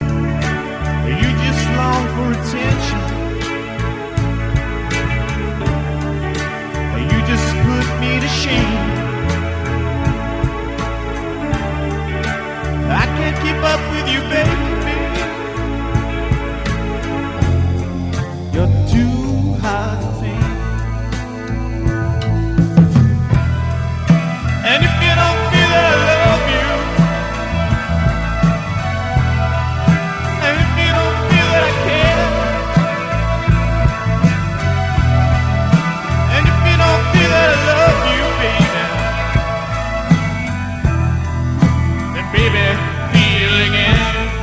croons lead vocals
drums
keyboards